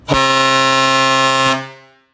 Nedking 95cm RVS luchthoorn ”The Sound of Grover” – Train Horns Nederland
Aantal dB’s: 120 dB